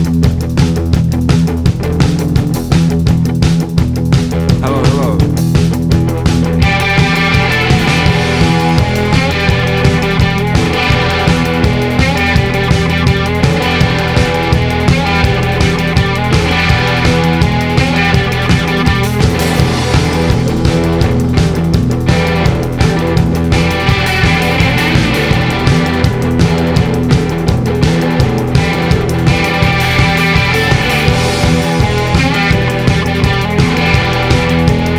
Жанр: Рок / Альтернатива